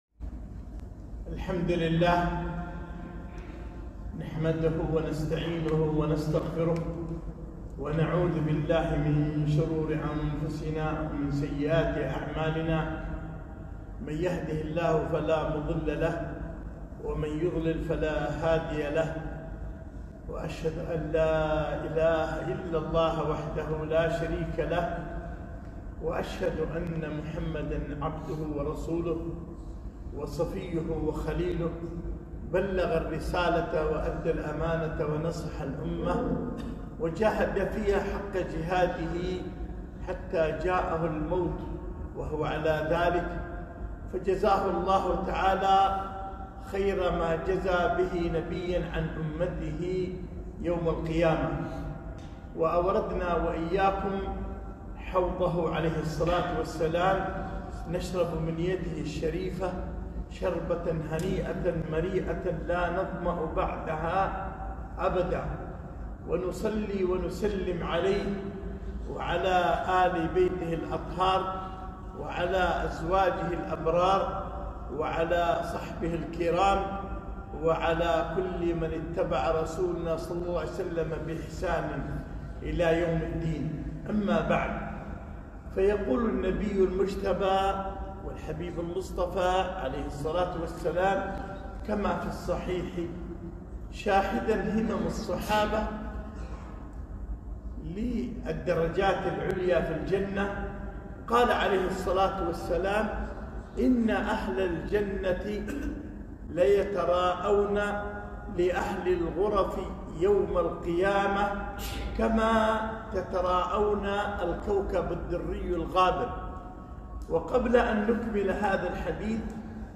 خطبة - الأجور العظيمة في صلاة الجماعة